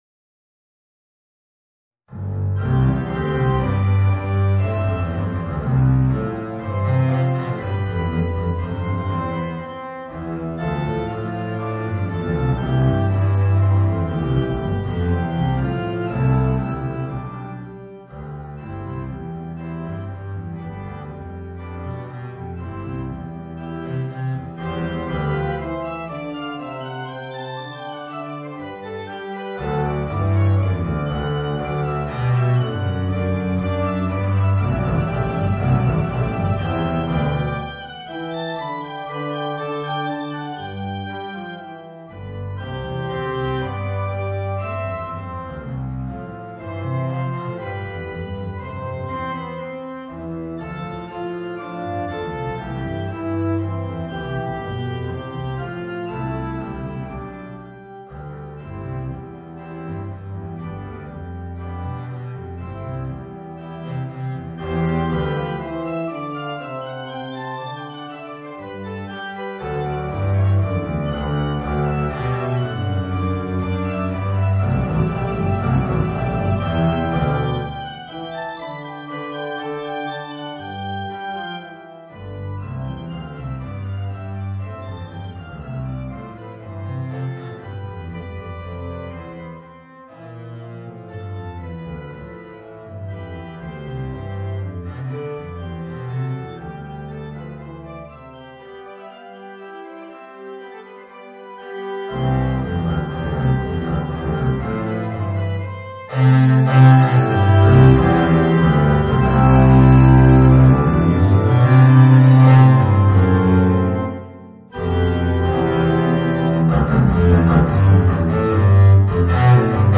Voicing: Contrabass and Piano